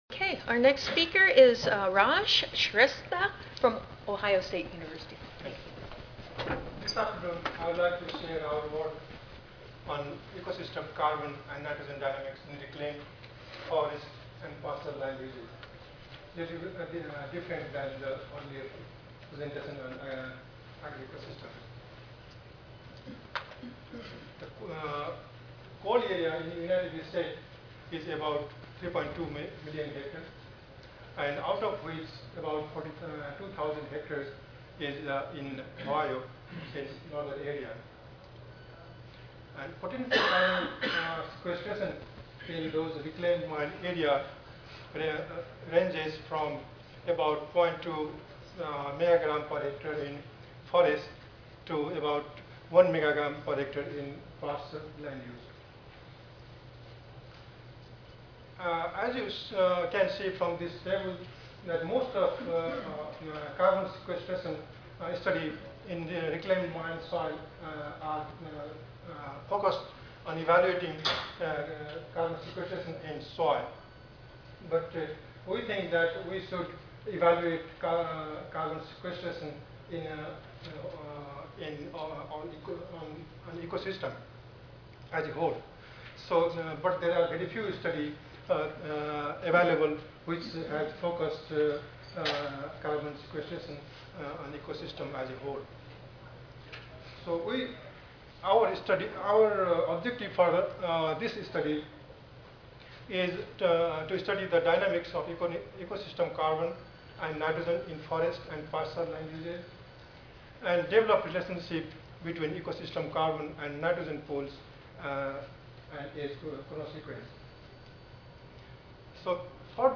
Oral Session
Audio File Recorded presentation